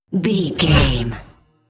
Pass by sci fi fast
Sound Effects
Fast
futuristic
pass by
vehicle